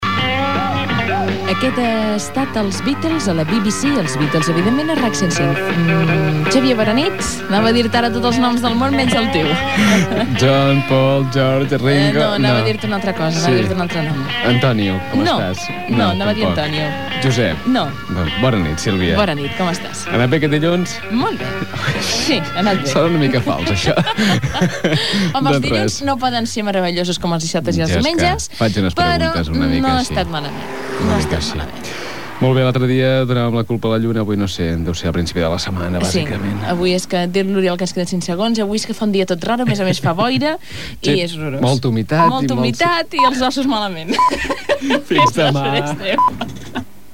Diàleg entre els presentadors en el moment del rellleu davant del micròfon
Musical
FM